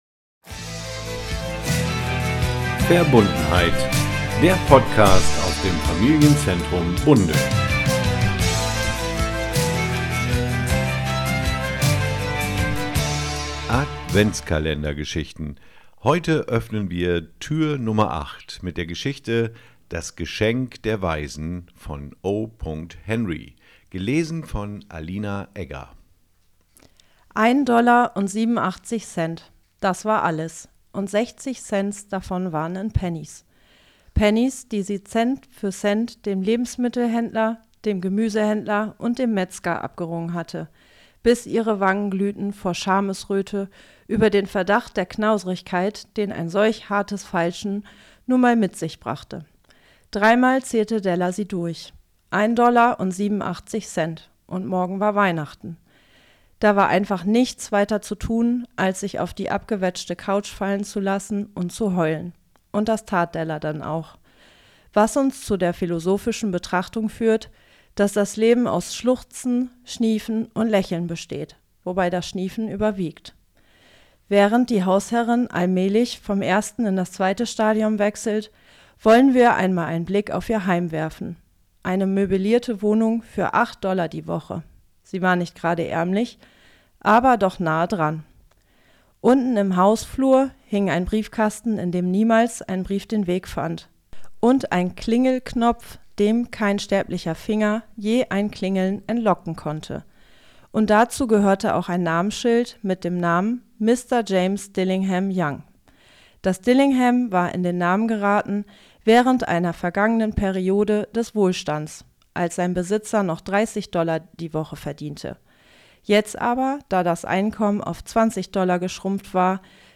Das Geschenk der Weisen von O. Henry. Gelesen von